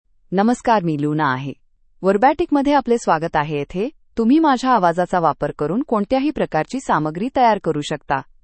FemaleMarathi (India)
Luna — Female Marathi AI voice
Luna is a female AI voice for Marathi (India).
Voice sample
Luna delivers clear pronunciation with authentic India Marathi intonation, making your content sound professionally produced.